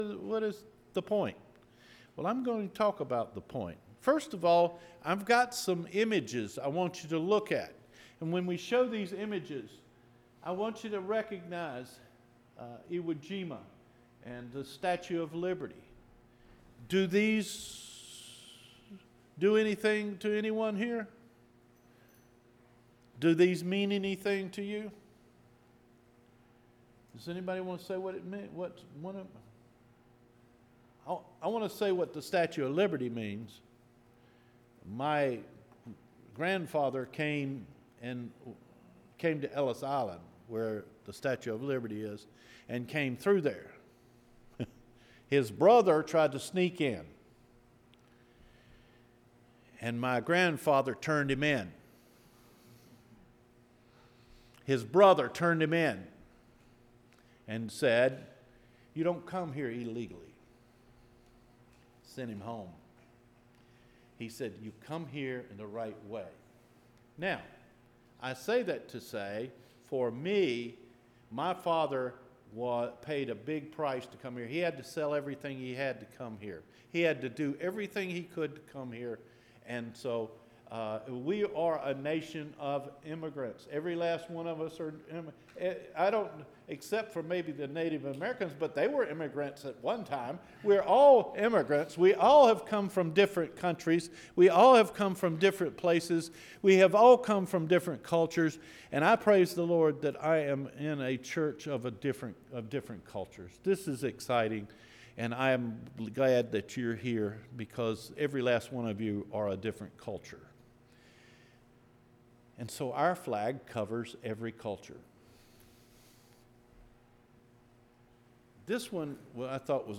SHOULD A CHRISTIAN BE A PATRIOT – JUNE 30 SERMON